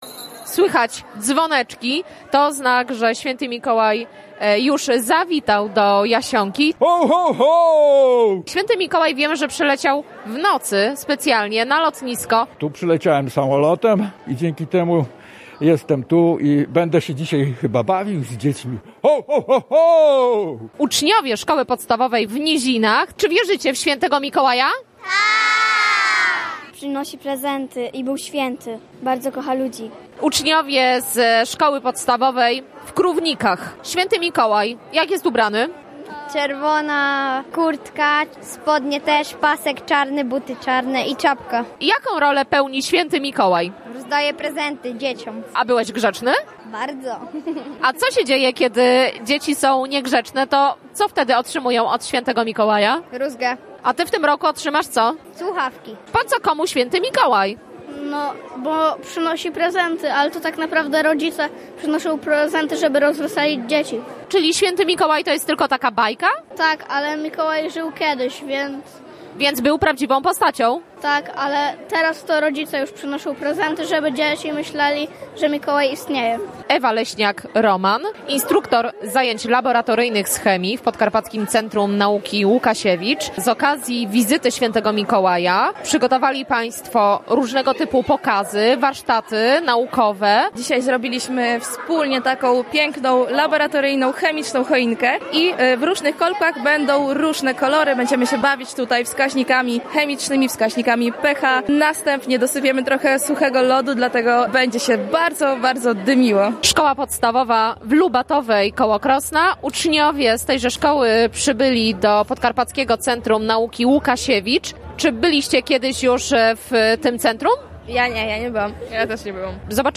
Relację z wydarzenia